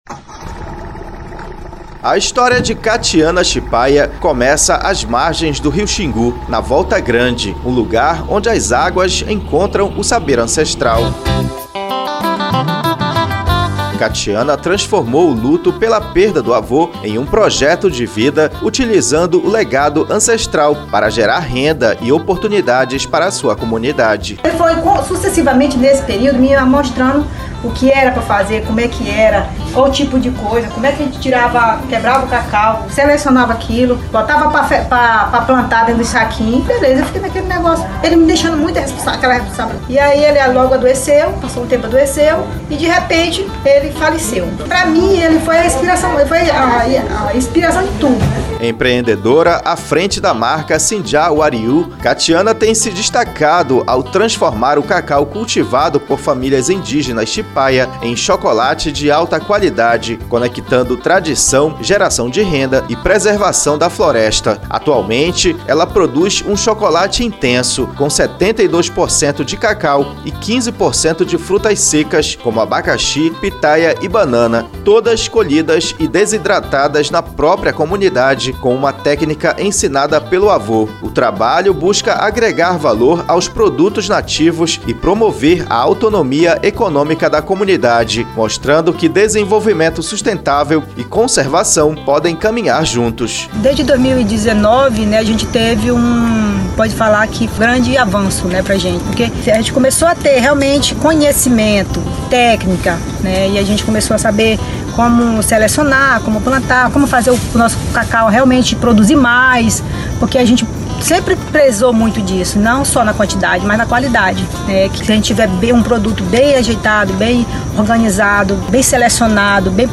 Na segunda reportagem da série sobre a usina hidrelétrica de Belo Monte, você vai conhecer um outro tipo de energia: aquela que transforma vidas. Comunidades indígenas, ribeirinhas e urbanas de Altamira, sudoeste do Pará,  vivenciam mudanças profundas com a chegada da usina. Moradias, infraestrutura, incentivo à cultura e ao empreendedorismo fazem parte de um ciclo de desenvolvimento social sustentável.